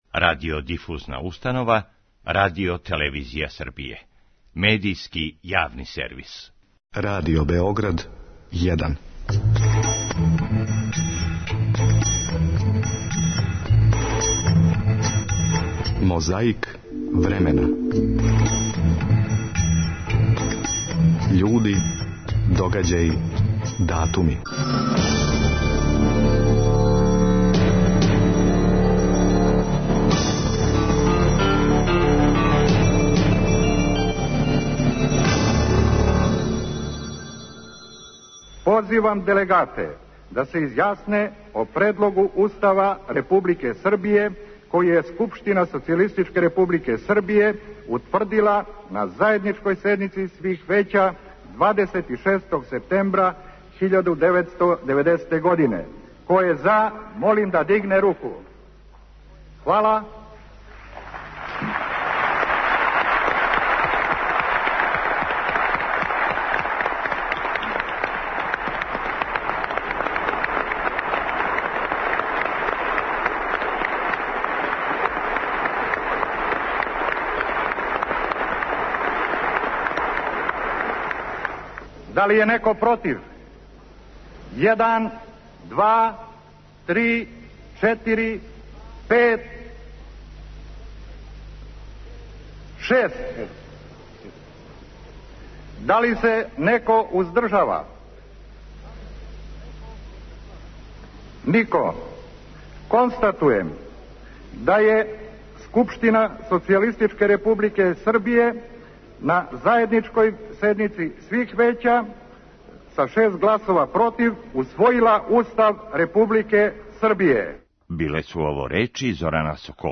У Љубљани је 27. септембра 1971. године, на Четвртом конгресу југословенских бораца из редова међународних бригада у Шпанском грађанском рату, говорила Долорес Ибарури.
Подсећа на прошлост (културну, историјску, политичку, спортску и сваку другу) уз помоћ материјала из Тонског архива, Документације и библиотеке Радио Београда.